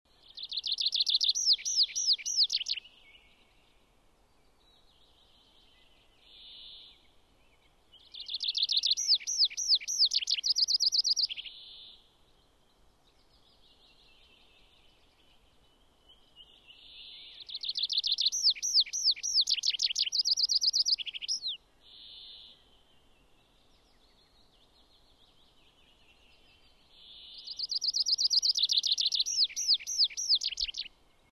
Boompieper
Boompieper.mp3